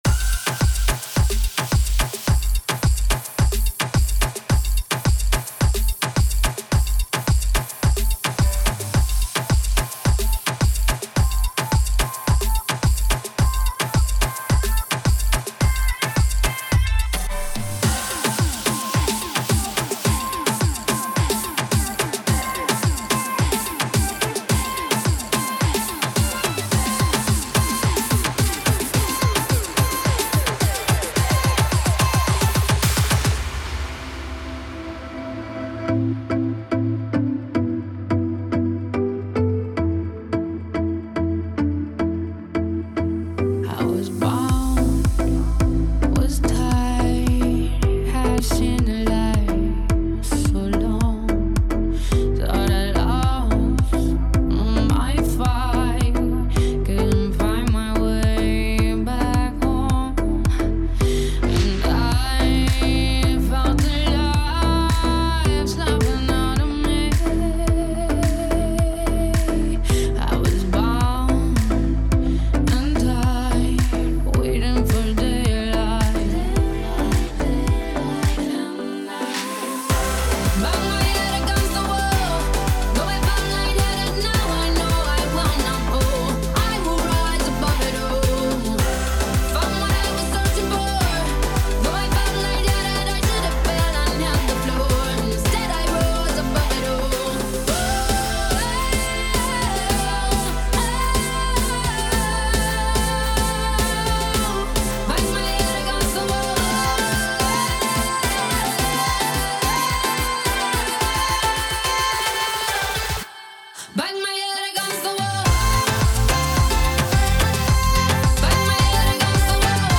Party DJ aus karlskron Ich bin DJ